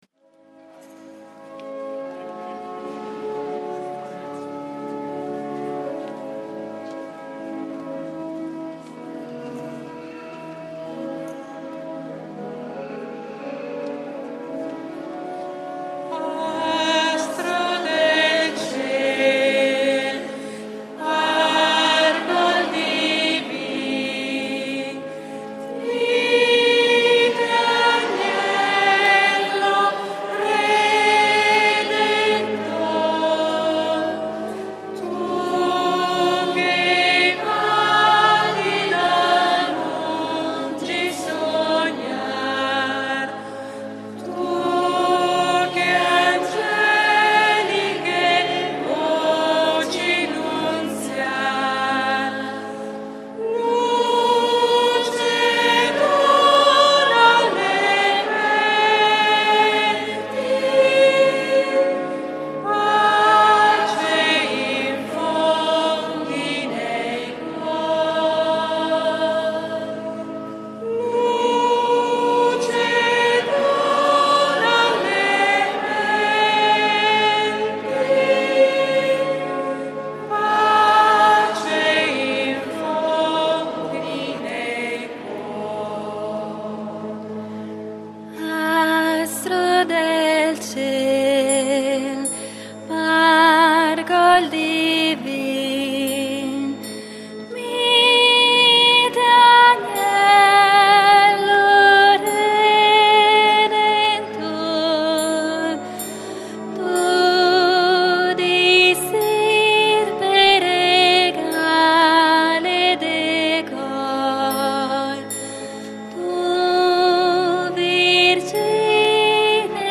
EPIFANIA DEL SIGNORE (con i ragazzi)